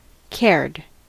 Ääntäminen
Ääntäminen US US : IPA : /kɛɹd/ RP : IPA : /kɛəd/ Haettu sana löytyi näillä lähdekielillä: englanti Käännöksiä ei löytynyt valitulle kohdekielelle. Cared on sanan care partisiipin perfekti.